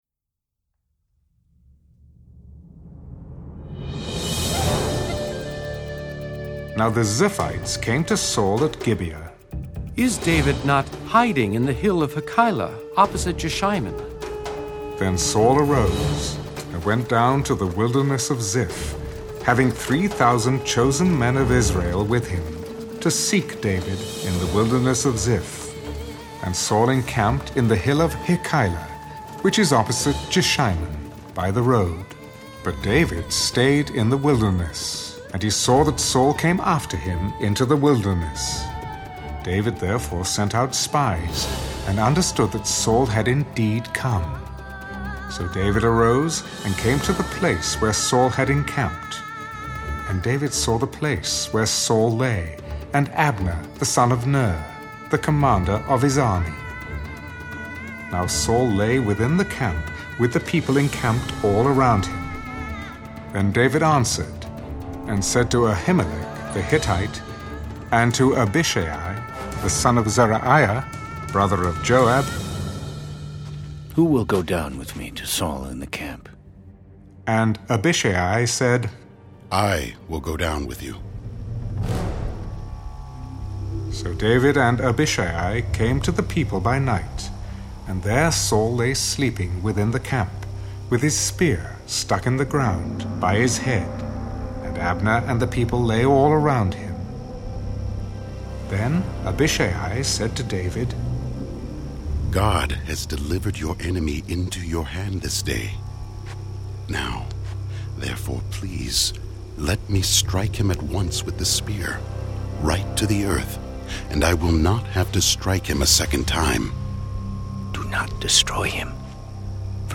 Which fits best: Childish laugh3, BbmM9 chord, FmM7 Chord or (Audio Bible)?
(Audio Bible)